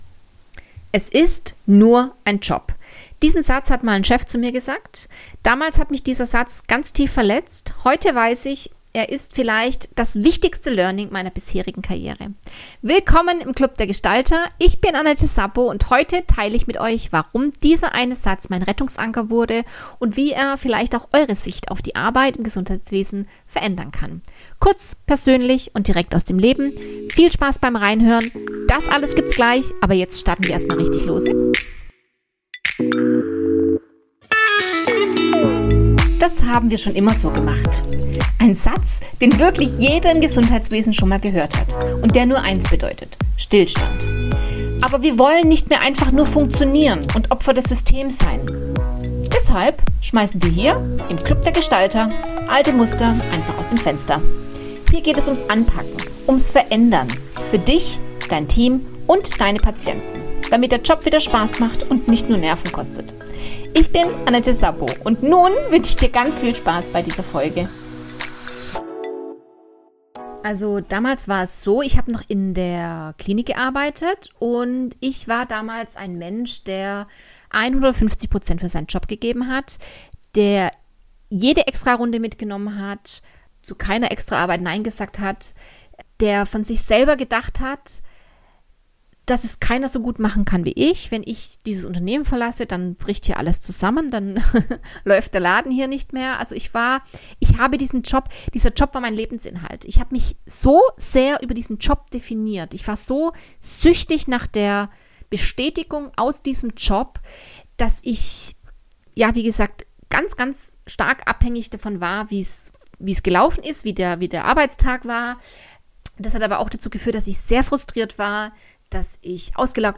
[Solo] „Es ist nur ein Job“ – Dein wichtigstes Schutzschild ~ Das haben wir schon IMMER so gemacht! Podcast